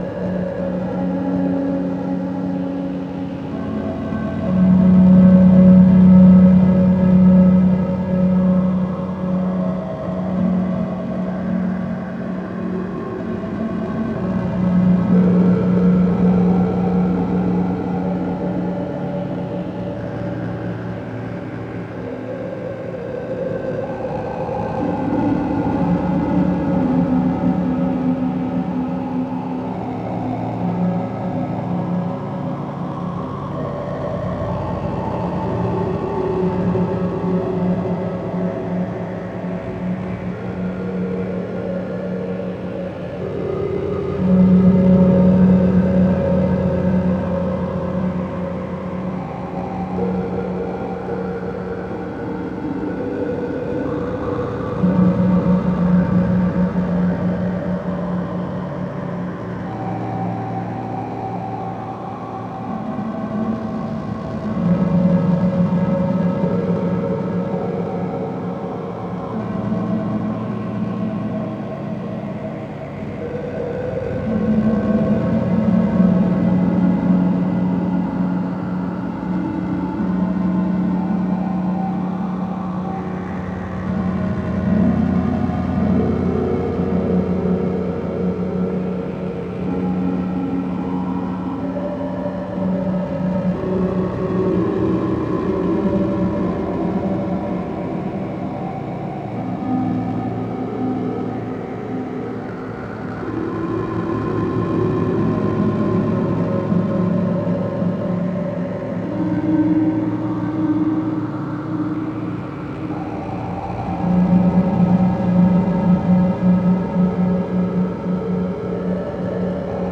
Une sorte de grosse drone machine.
J'ai mis quatre oscillateurs dans le machin.